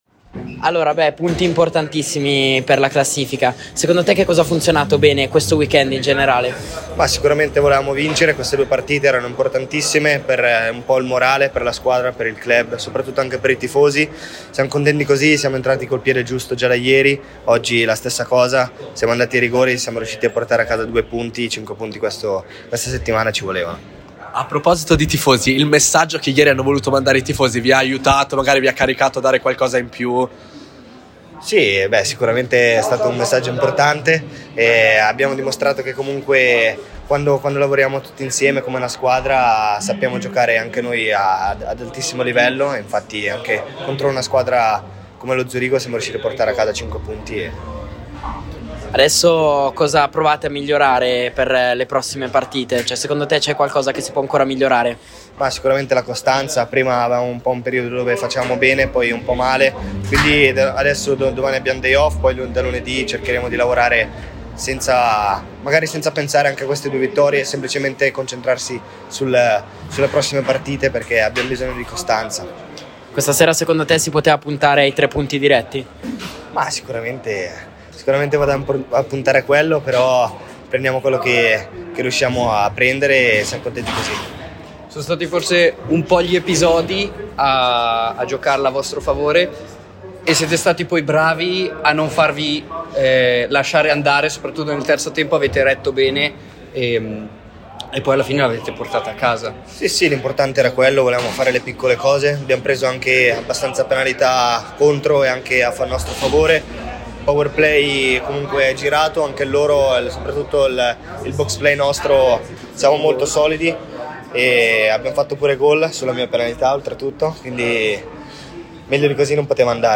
Radio Lime sport ringrazia lo staff dello ZSC Lions per averci permesso di commentare alla meravigliosa Swiss Life Arena e vi augura un buon ascolto!
Interviste: